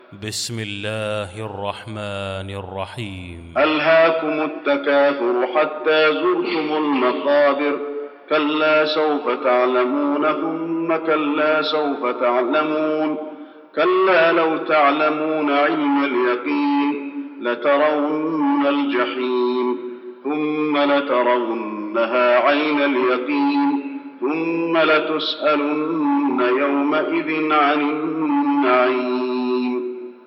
المكان: المسجد النبوي التكاثر